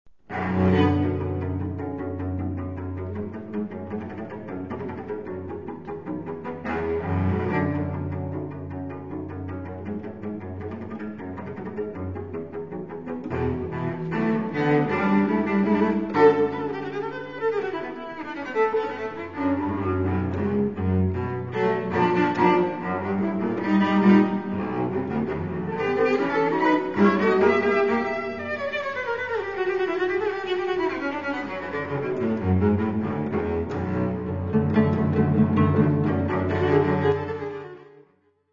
Le climat est tout à tour rebelle, passionné, dramatique !